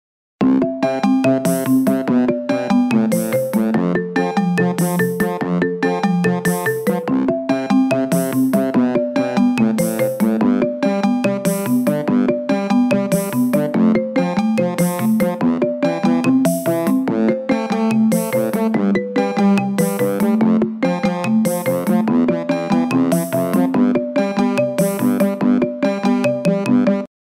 Звуки будильника iPhone